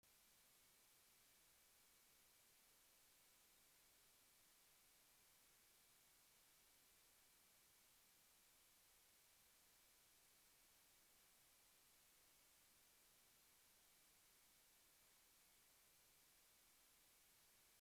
Hiss in any USB mics and interface
I have 2 USB mics and then 1 analogue mic for which I use an interface, and no matter which device I plug into my laptop, I get a terrible high pitched hiss (almost like wind), even when turning down the volume of the mic completely. The interface makes a hissing noise even without a mic being plugged in - as soon as I select it as my recording channel, it starts to hiss.
I have attached a sample of the hiss that I get.